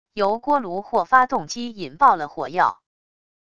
由锅炉或发动机引爆了火药wav音频